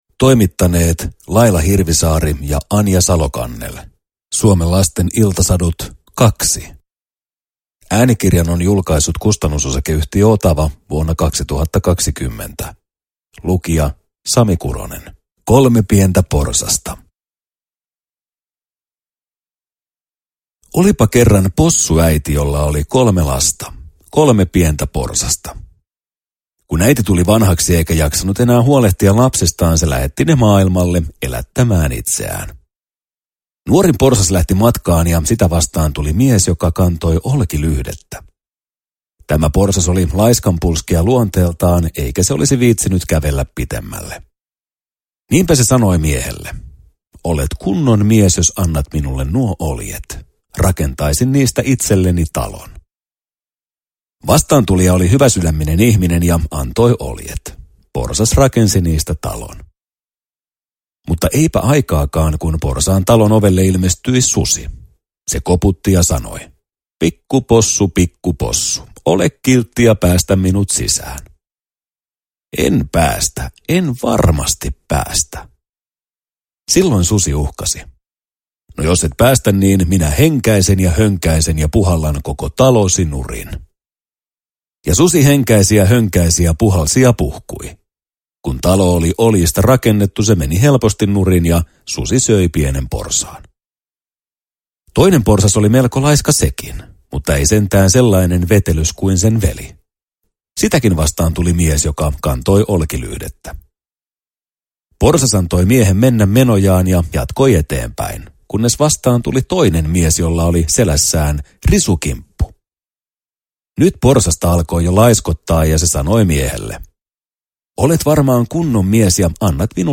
Suomen lasten iltasadut 2 – Ljudbok – Laddas ner